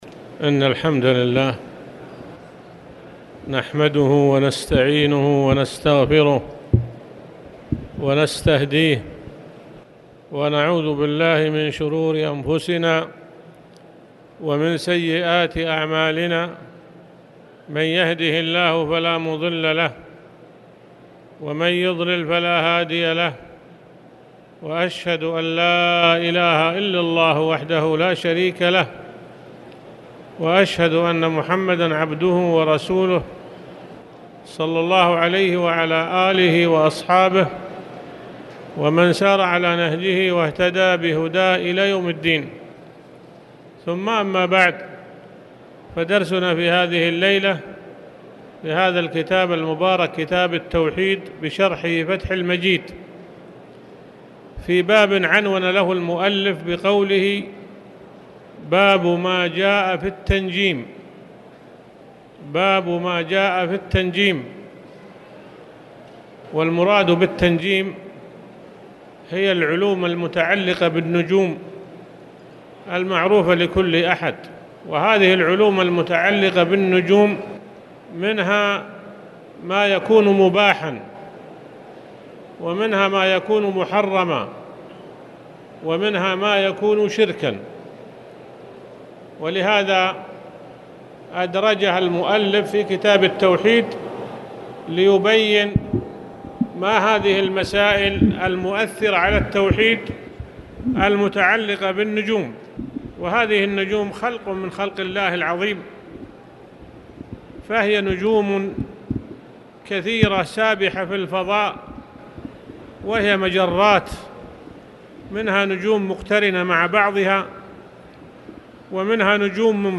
تاريخ النشر ٢٧ شعبان ١٤٣٨ هـ المكان: المسجد الحرام الشيخ